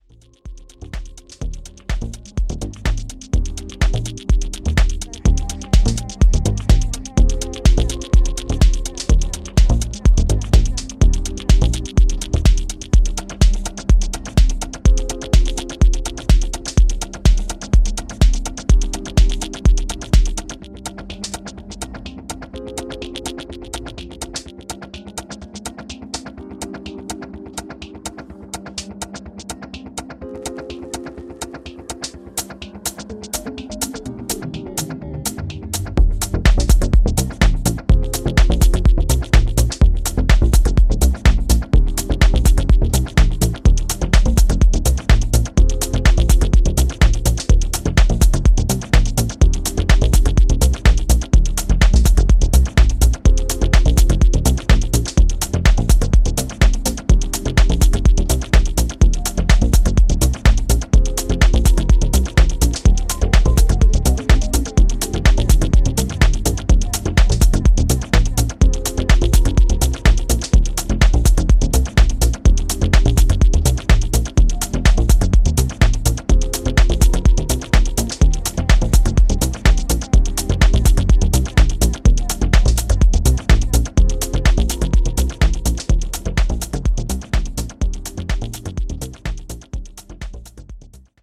Groovy, Deep Minimal Tracks On Each Side.